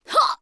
fall_1.wav